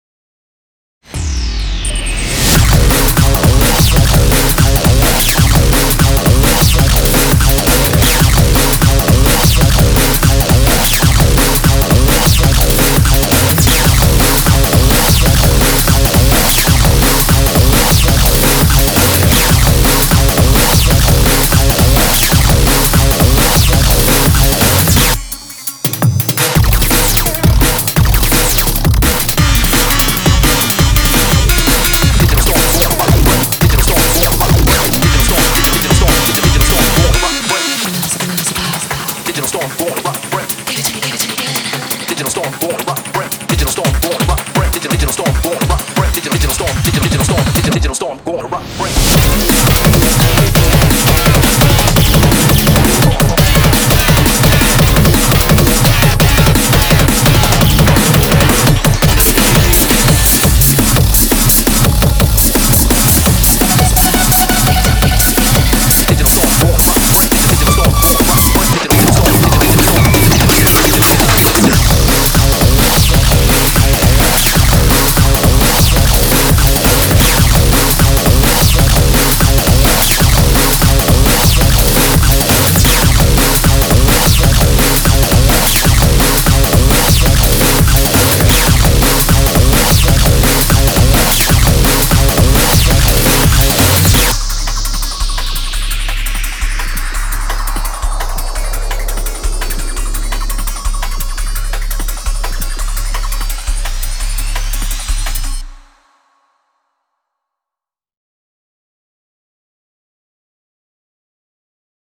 BPM170
Audio QualityPerfect (High Quality)
Genre: BIGCORE.